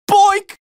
Boink Boink Bonk Tf2 Téléchargement d'Effet Sonore